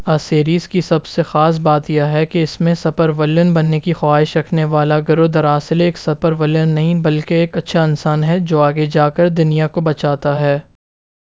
Spoofed_TTS/Speaker_08/103.wav · CSALT/deepfake_detection_dataset_urdu at main